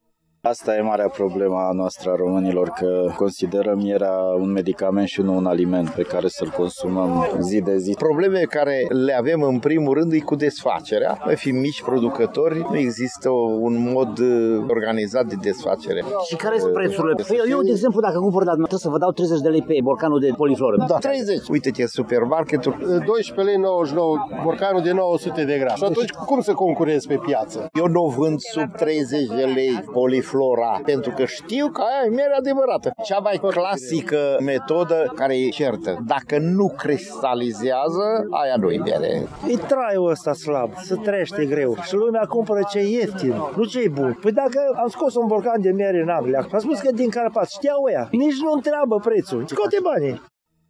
Cei care au trecut pe la standurile Târgului Apicol de la Tg.Mureș sunt clienți vechi, majoritatea de vârsta a doua și a treia :